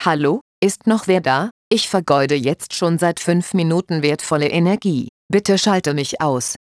hier mal eine mögliche Sprachmeldung für die neue Sonderfunktionalität "Inaktivitätsalarm" 5 / 10 / 15 Minuten oder ohne Angabe von Zeit